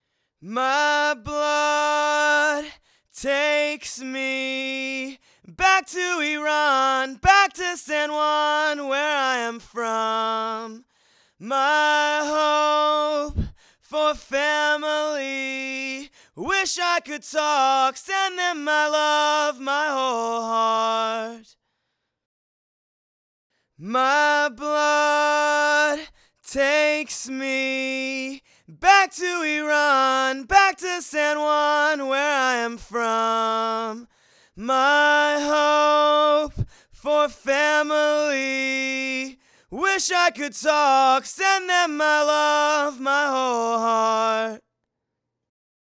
Ok, here's a vocal test. The first bit is with the Sennheiser e835, the second bit is with the RE320 (in normal mode--I didn't bother with the switch for this test). I am running it through a Focusrite ISA One on the ISA 110 impedance setting. Personally, I think the RE320 is a lot clearer and a bit more in your face, which I like.